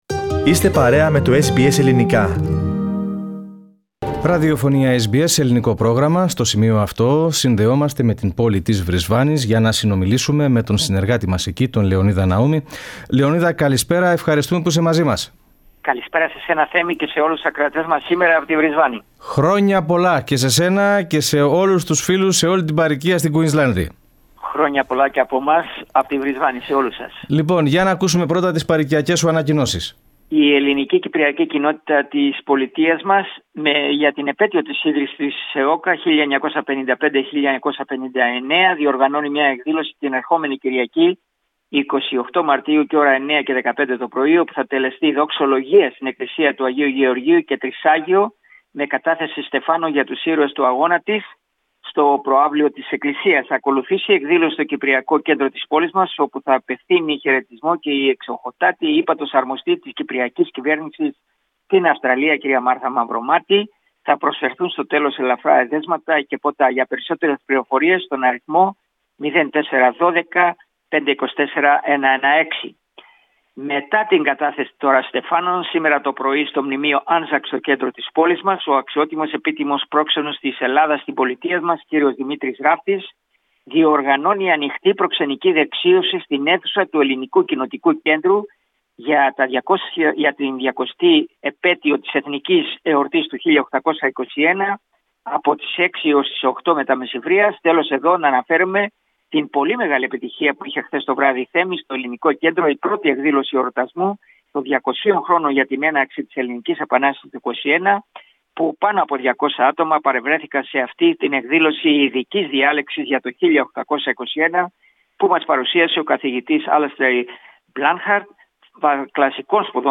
SBS Ελληνικά